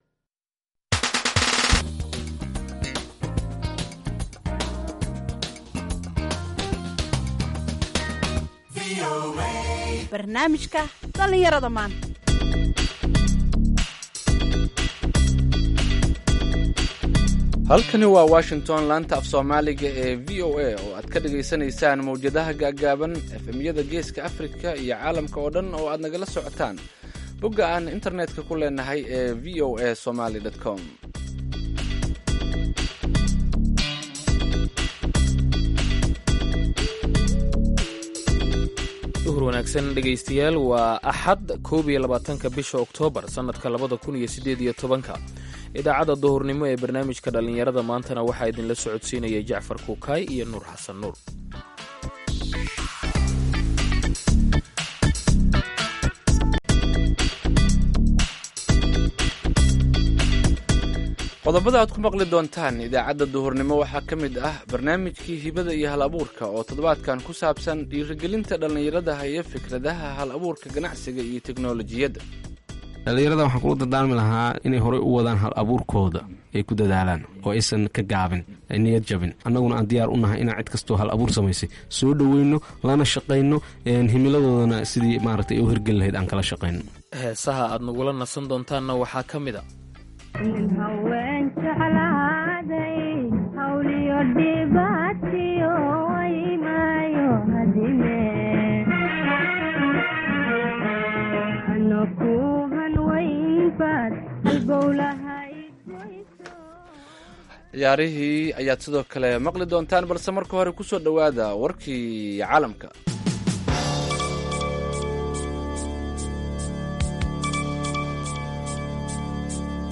Idaacadda Duhurnimo waxaad qeybta hore ku maqli kartaa wararka ugu waaweyn ee Soomaaliya iyo Caalamka. Qeybta danbe ee idaacaddu waxay idiin soo gudbinaysaa barnaamijyo ku saabsan dhalinyarada maanta.